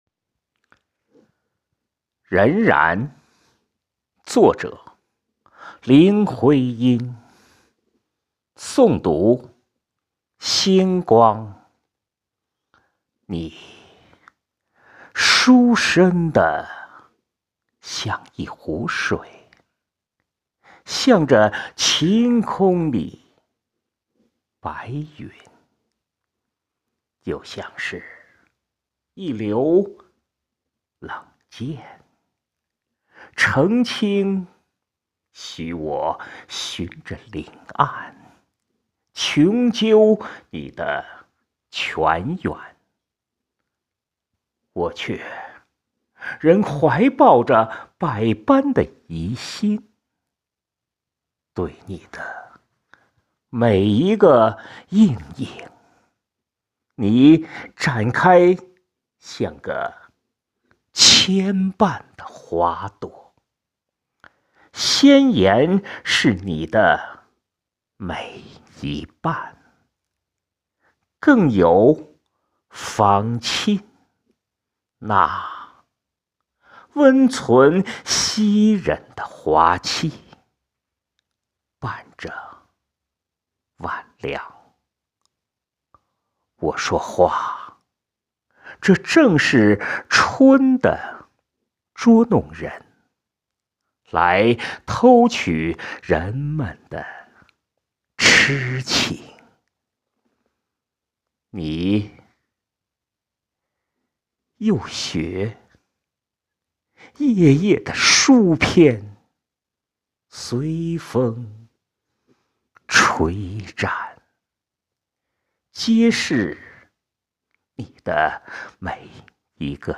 （真实不加修饰的好原声）